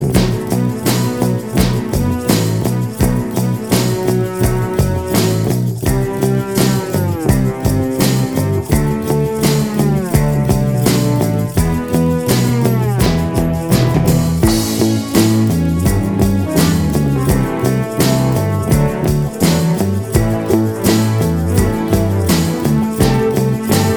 Minus Lead Pop (1960s) 4:34 Buy £1.50